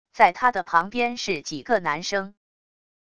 在他的旁边是几个男生wav音频生成系统WAV Audio Player